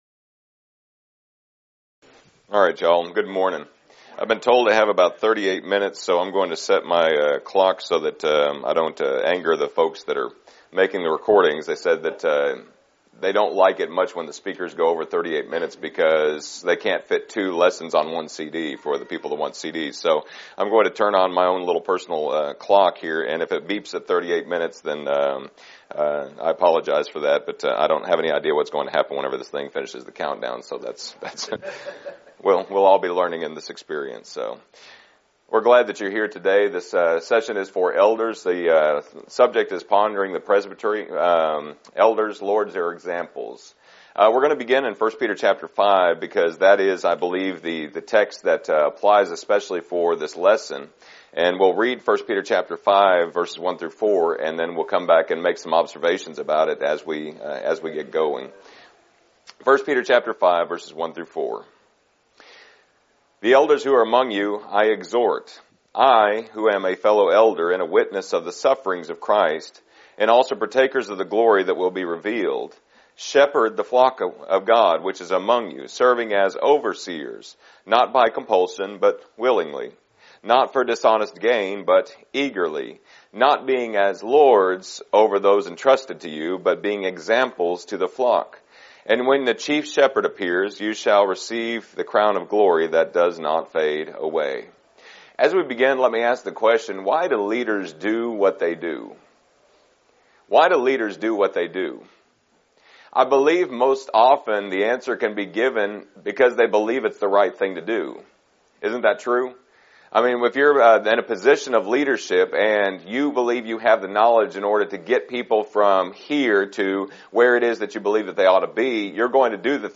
Event: 2019 Focal Point
Preacher's Workshop
lecture